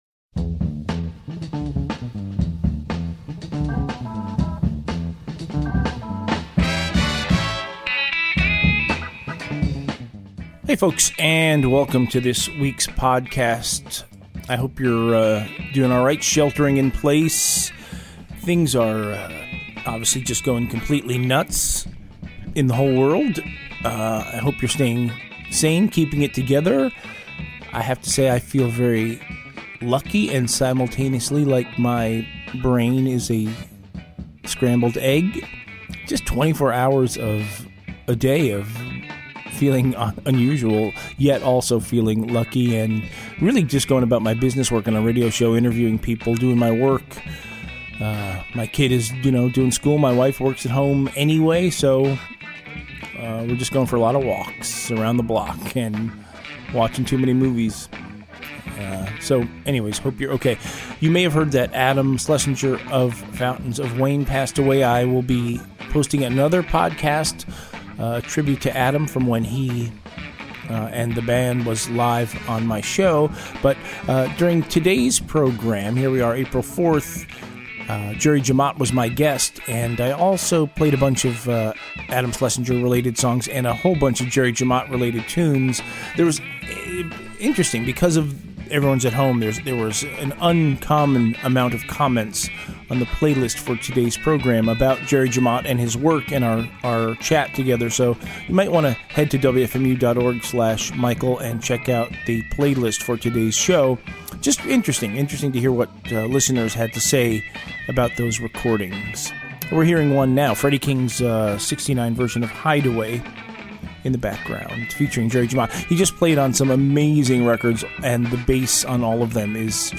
Guest: Session bassist Jerry Jemmott from Apr 4, 2020
Jerry Jemmott - "Interview"